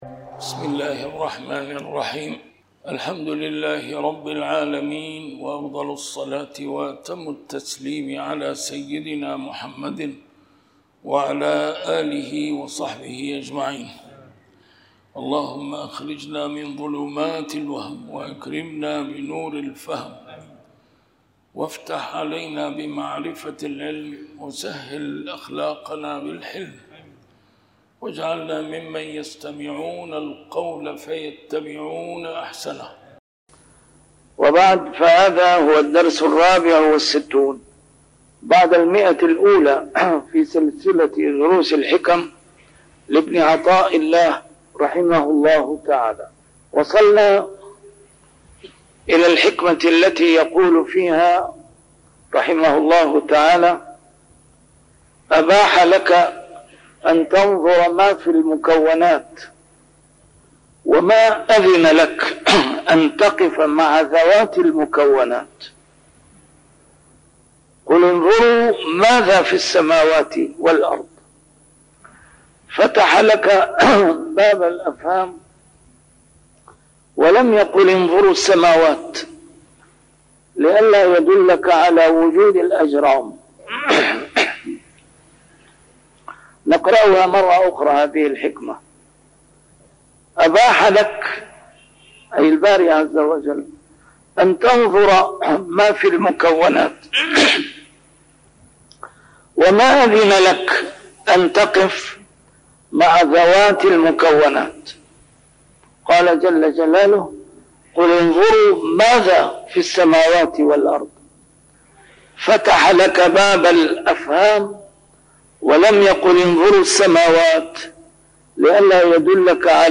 A MARTYR SCHOLAR: IMAM MUHAMMAD SAEED RAMADAN AL-BOUTI - الدروس العلمية - شرح الحكم العطائية - الدرس رقم 164 شرح الحكمة 140